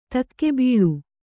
♪ takke bīḷu